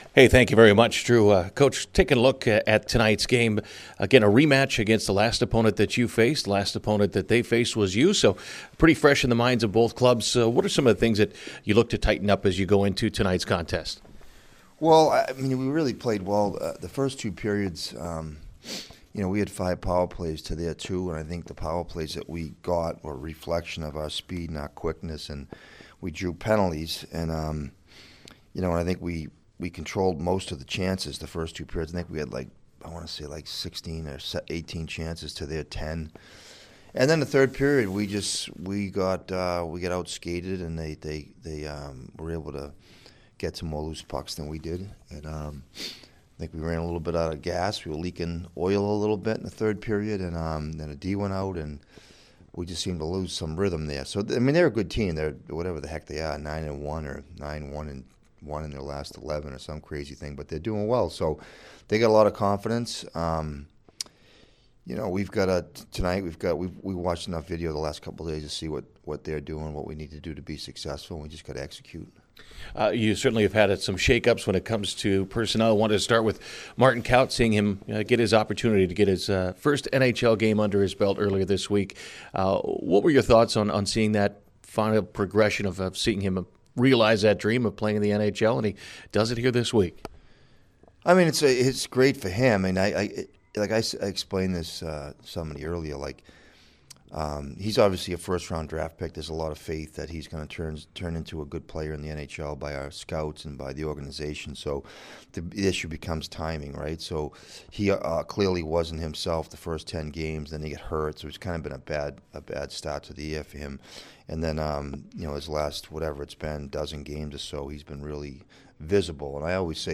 Game Day Interview